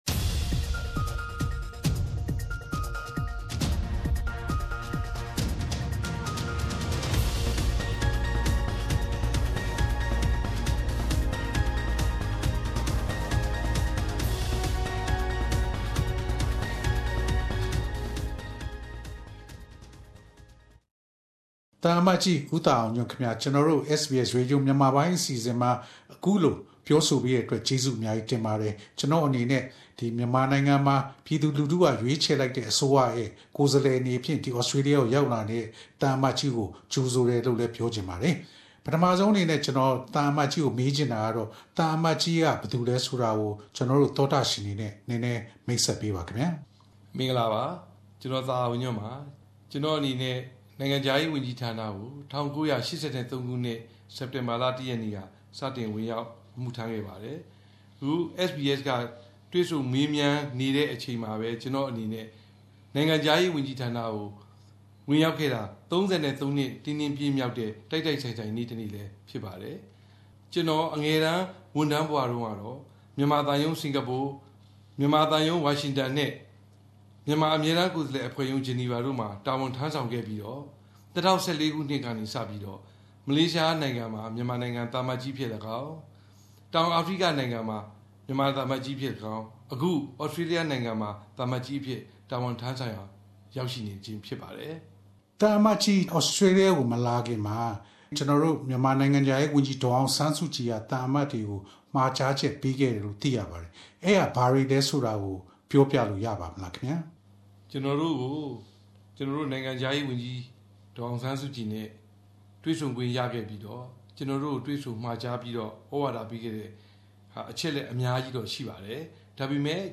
Interview with the Myanmar Ambassador to Australia